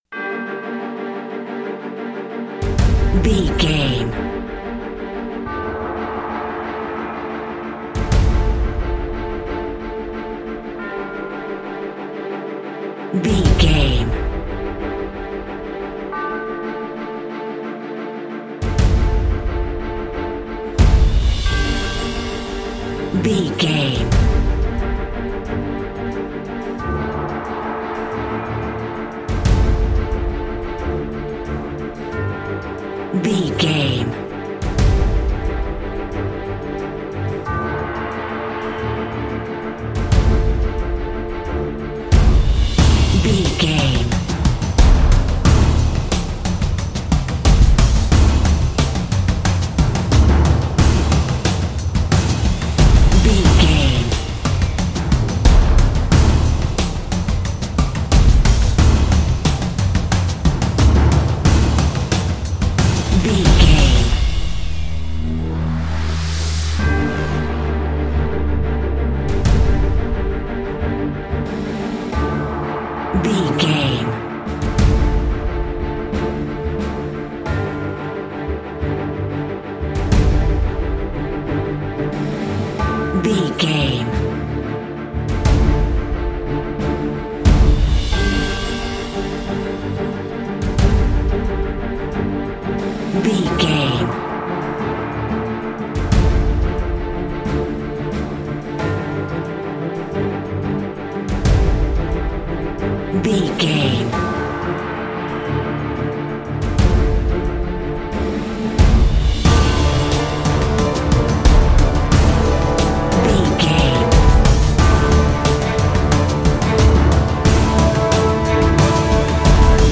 Epic / Action
Aeolian/Minor
D
strings
drums
percussion
cello
violin
synthesiser
orchestral
orchestral hybrid
dubstep
aggressive
energetic
intense
synth effects
wobbles
driving drum beat